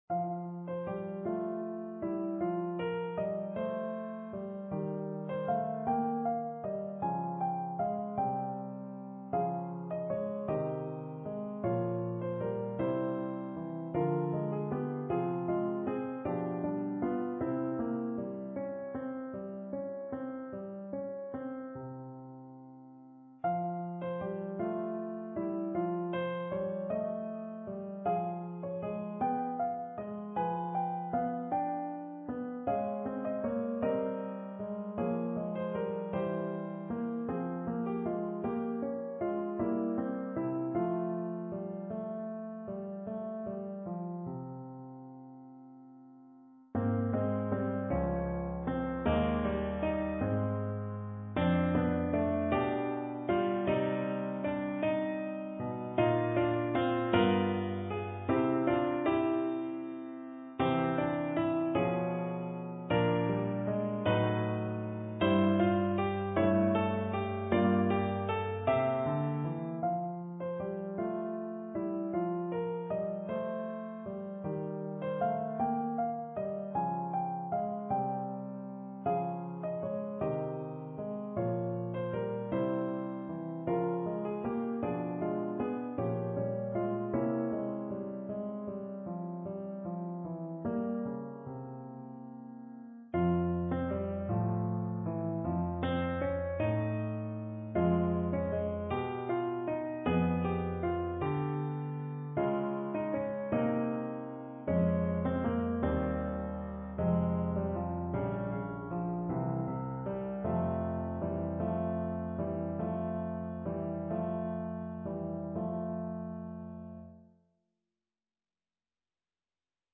for solo piano